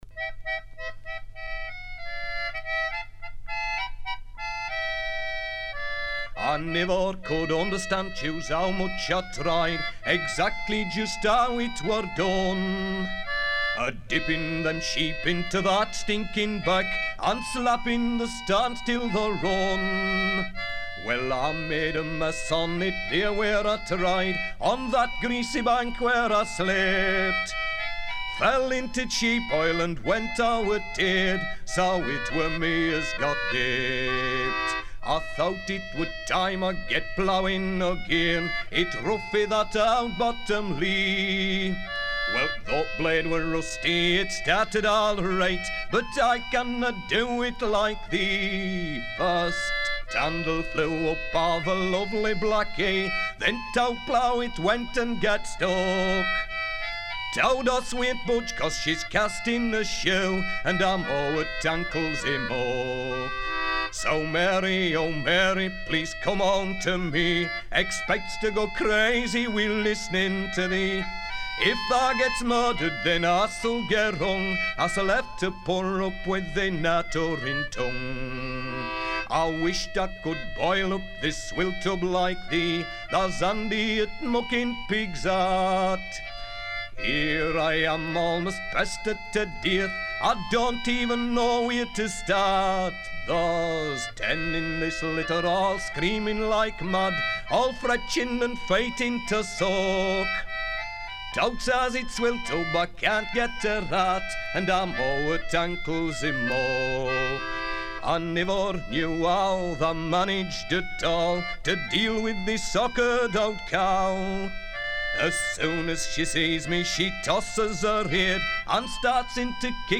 folk singer
his fine voice and lively interpretation of traditional English songs seemed to me to put him in the first rank of British singers.